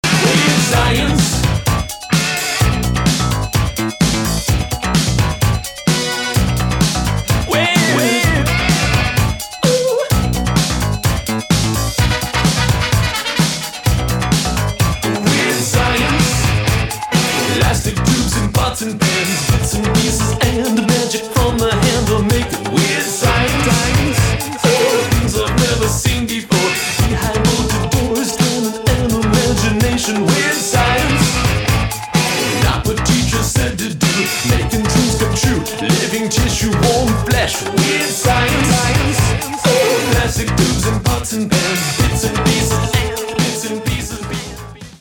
• Качество: 224, Stereo
веселые
80-е
new wave
post-punk